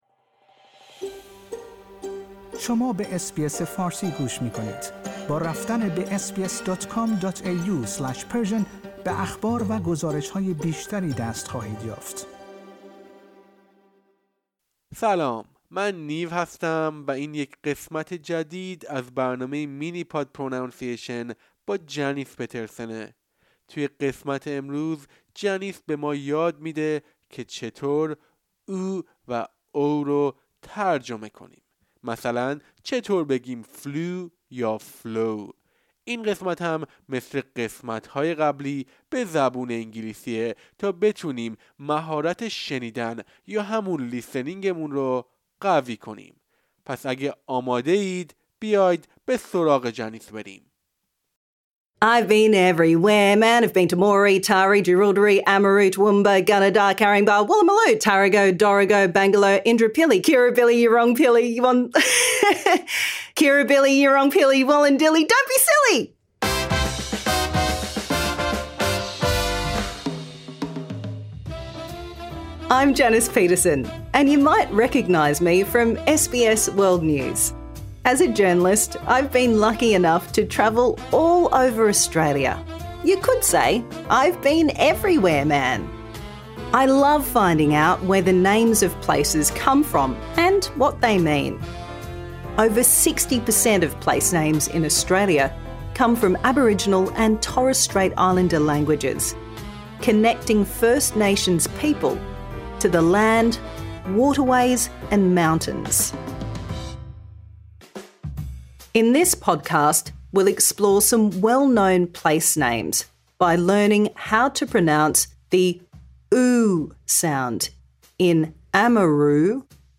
Improve your pronunciation | Season 2